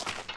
脚踩草地zth070522.wav
通用动作/01人物/01移动状态/06落叶地面/脚踩草地zth070522.wav
• 声道 單聲道 (1ch)